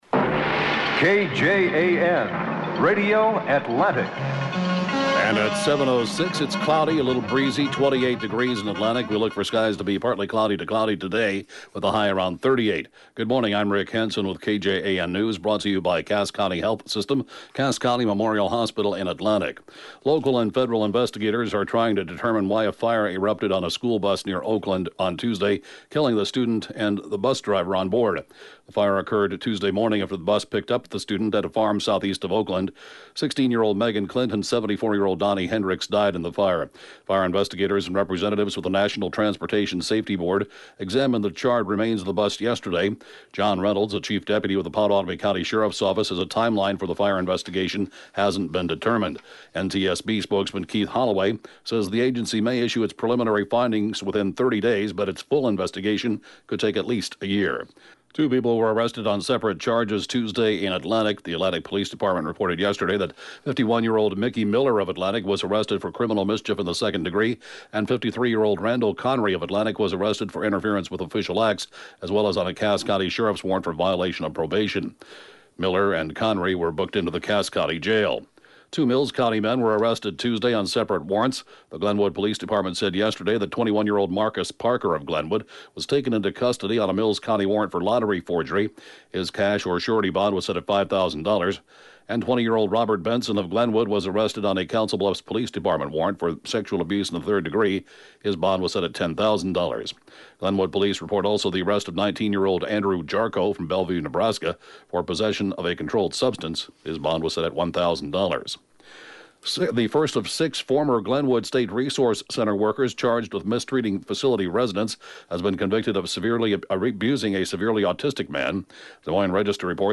(Podcast) KJAN Morning News & funeral report, 12/14/2017